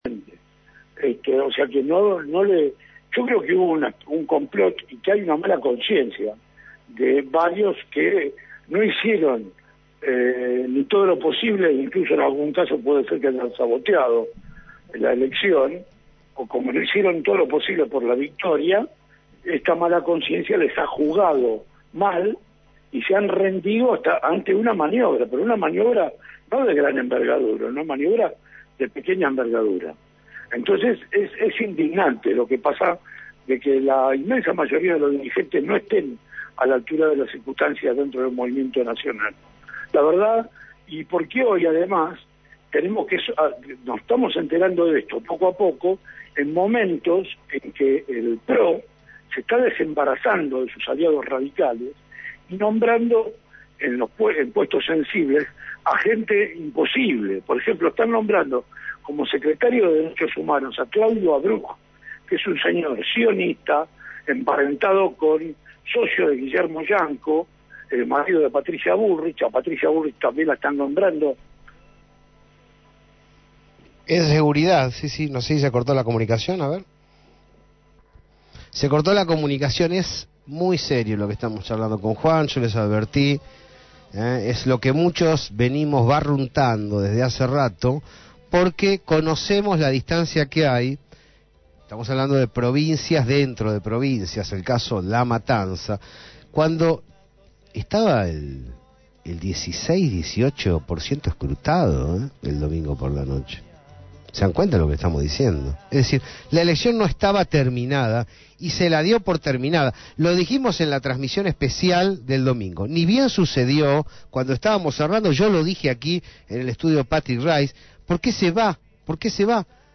Con tono filoso, ese fue el interrogante que planteó el periodista